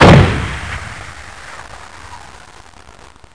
05073_Sound_DISPARO3
1 channel